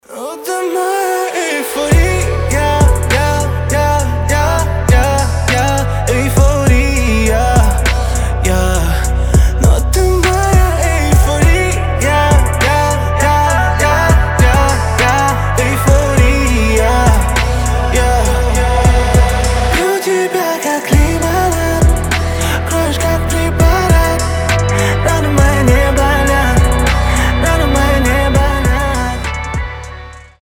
• Качество: 320, Stereo
лирика
красивый мужской голос
медленные